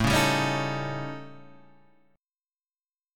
A 7th Flat 5th